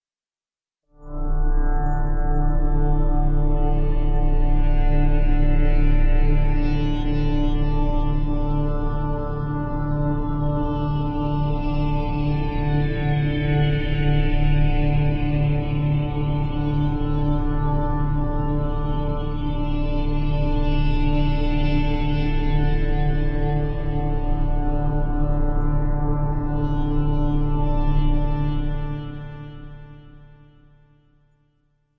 描述：使用vst乐器制作的科幻声音/无需归属 公共领域
标签： 未来 无人机 驱动器 背景 隆隆声 黑暗 冲动 效果 FX 急诊室 悬停 发动机 飞船 氛围 完善的设计 未来 机械 航天 sci-网络 电子 音景 环境 噪音 能源 飞船 大气
声道立体声